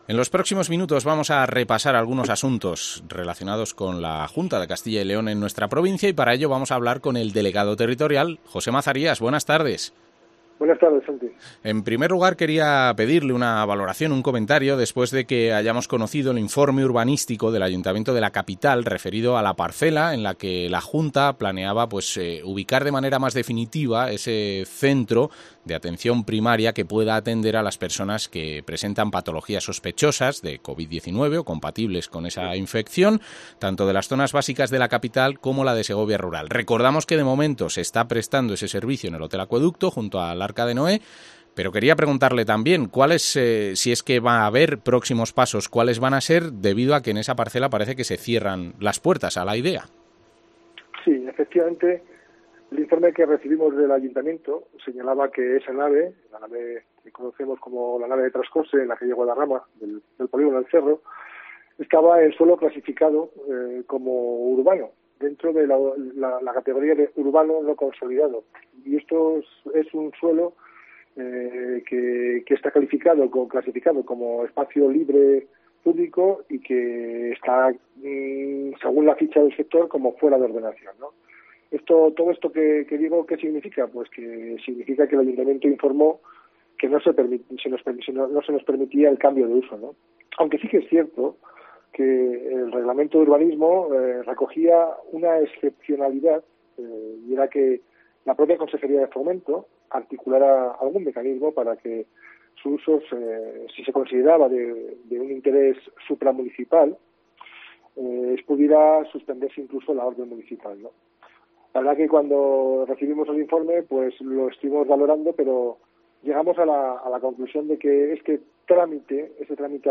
Entrevista al delegado territorial de la Junta, José Mazarías